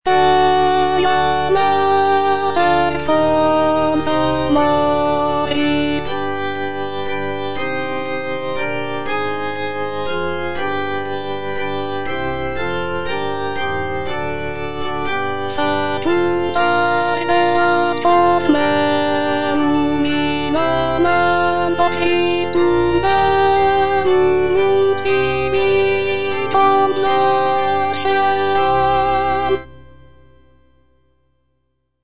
G.P. DA PALESTRINA - STABAT MATER Eja Mater, fons amoris (alto II) (Voice with metronome) Ads stop: auto-stop
sacred choral work